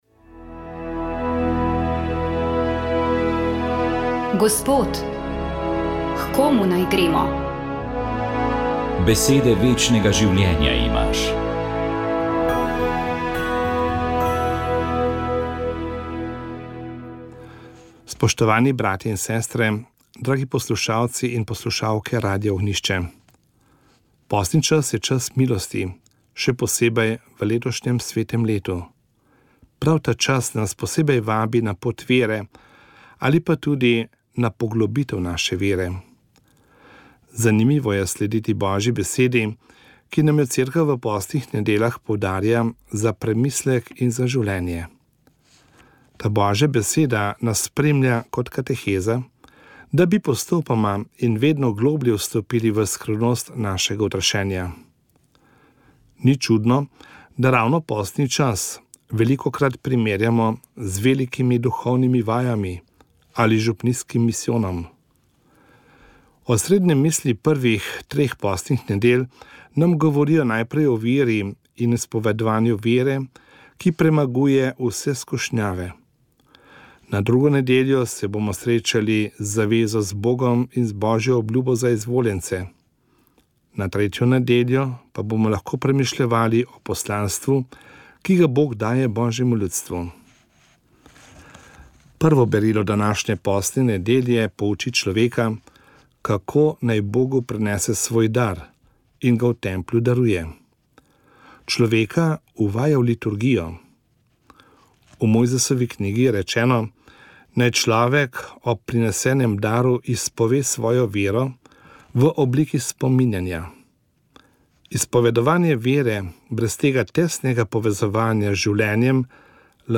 Duhovni nagovor
Škofovo razmisljanje ob evangeliju: